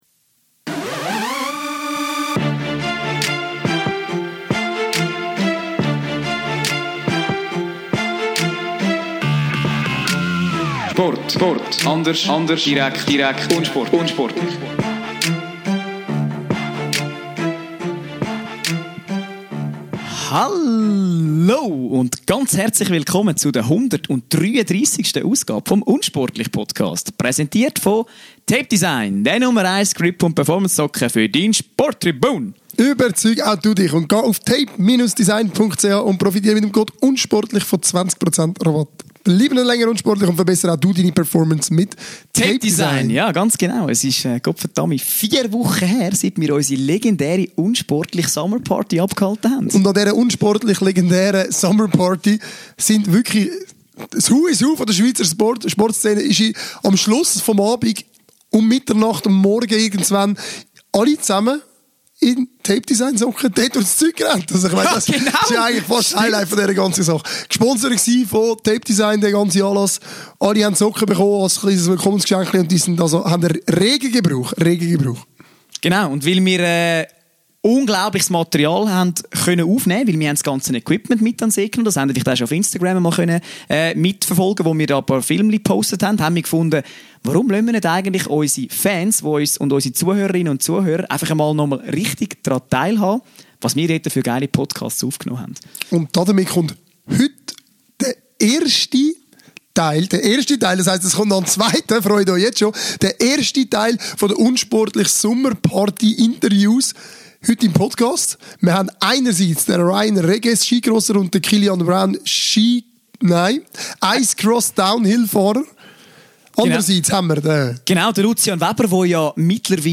Wir präsentieren euch die lang ersehnten Highlights der unsportlich Sommer Party!
Im feuchtfröhlichen Partyumfeld kommt es zu wundervollen Gesprächen, absurden Wetten, Schüssen in Richtung der Mainstream-Medien und vor allem – vielen vielen Lachern!